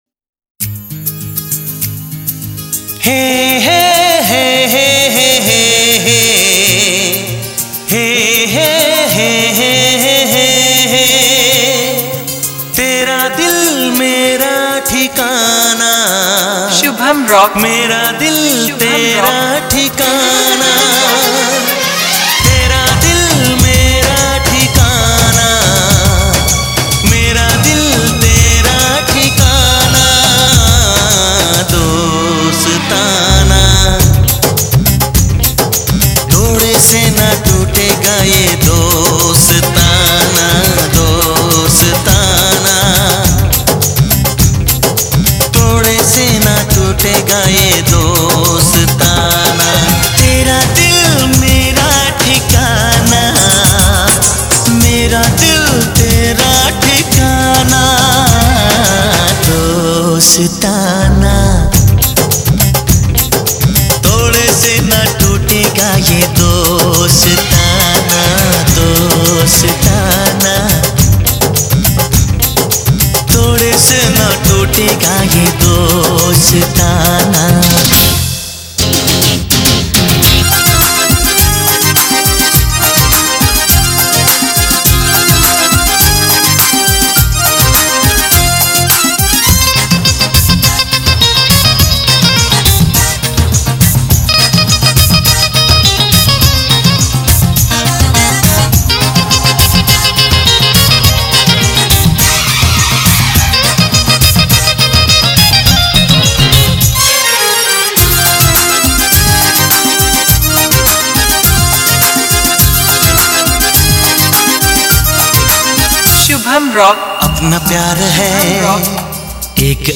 Mela Competition Filters Song